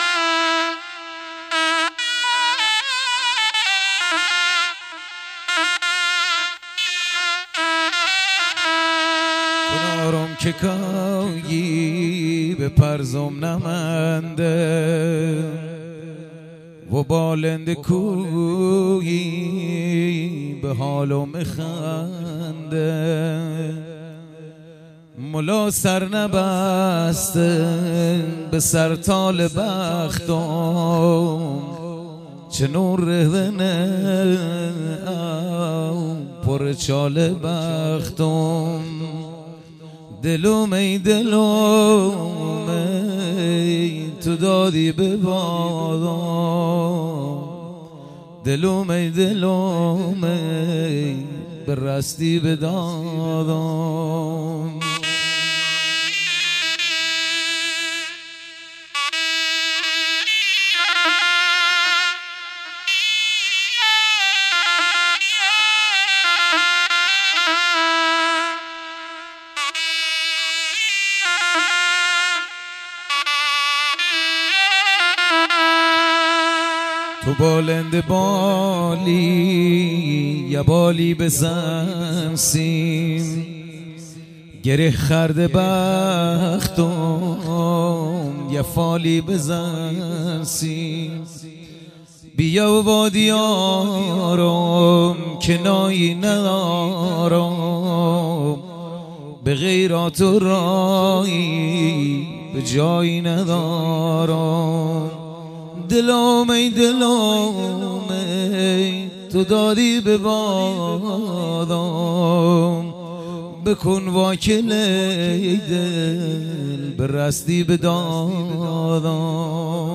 آهنگ بختیاری